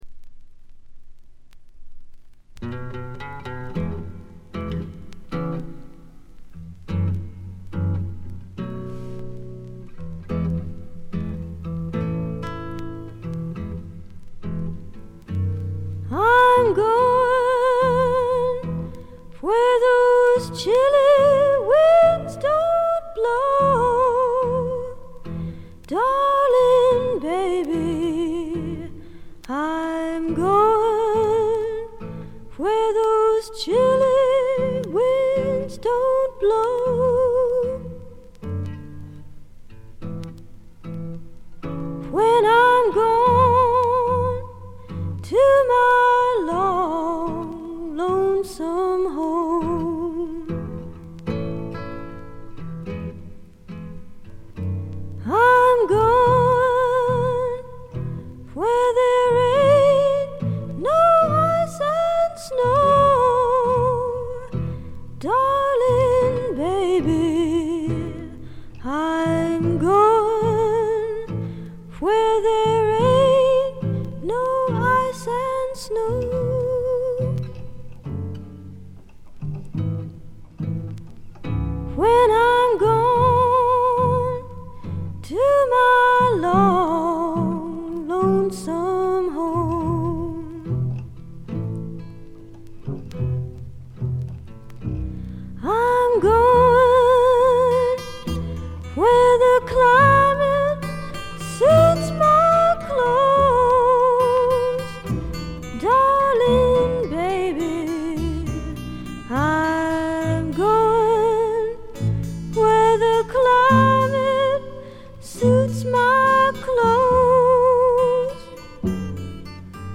軽微なバックグラウンドノイズやチリプチ。
美しくも素朴な味わいのあるソプラノ・ヴォイスに癒やされてください。
試聴曲は現品からの取り込み音源です。